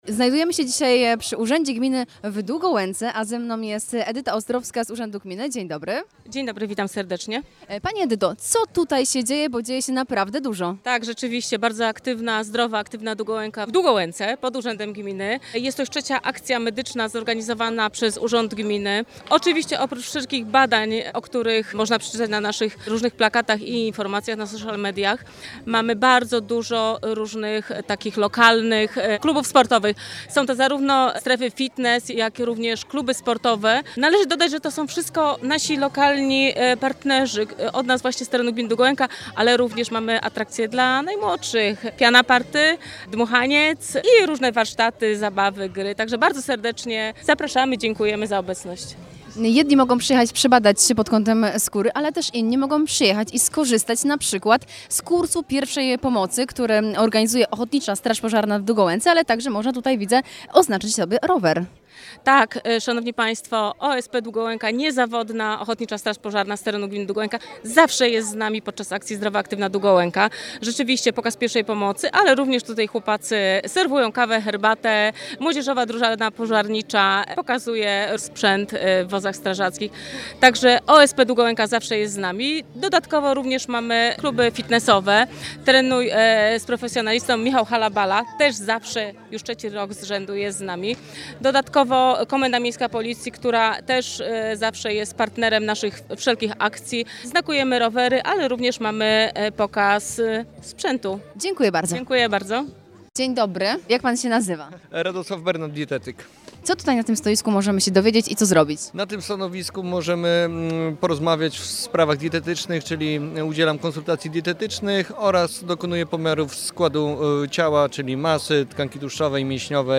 Zdrowa Aktywna Długołęka [relacja z wydarzenia]